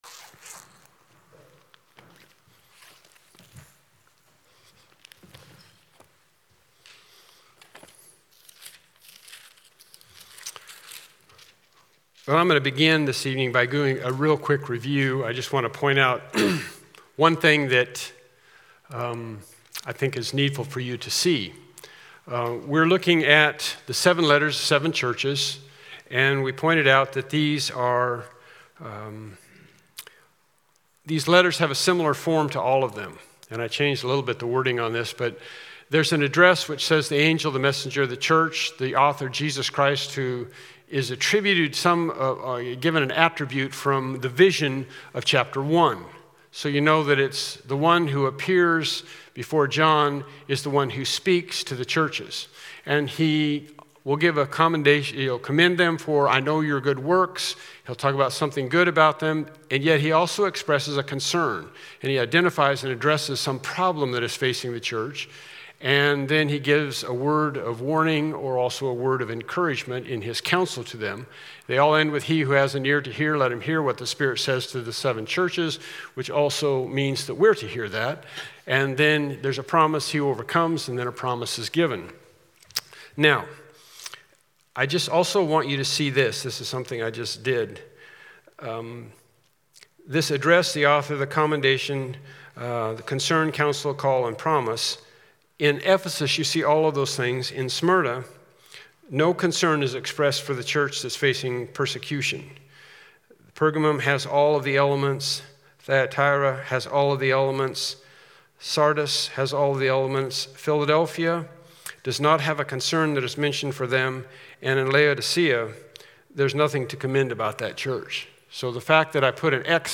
8 Service Type: Evening Worship Service « “All Hope in God” “All Hope in God